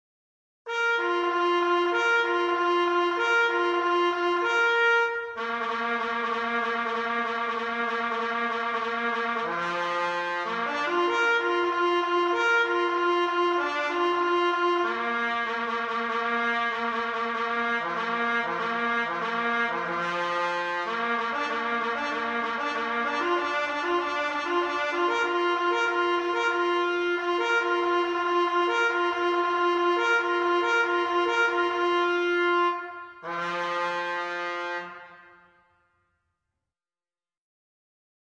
Traditional : El Deguello - a military bugle call: Sheet Music
This bugle call is Mexican in origin.